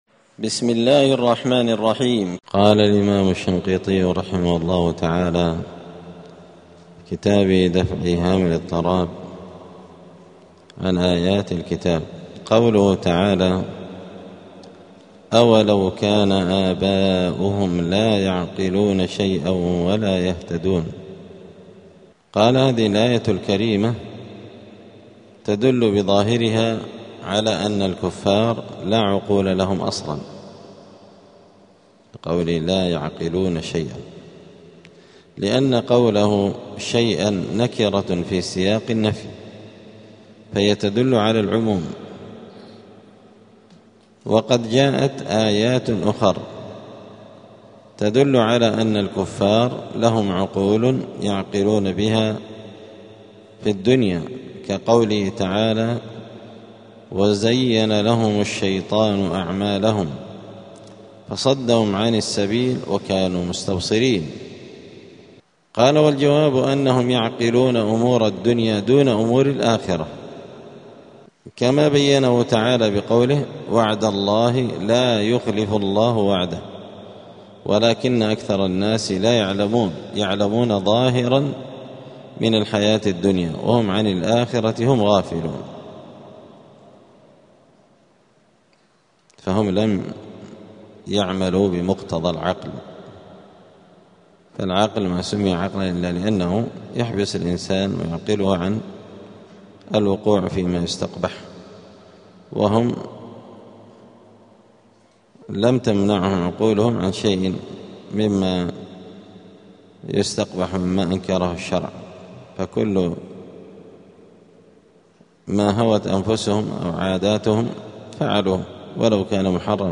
الأربعاء 15 رمضان 1447 هــــ | الدروس، دروس القران وعلومة، دفع إيهام الاضطراب عن آيات الكتاب | شارك بتعليقك | 5 المشاهدات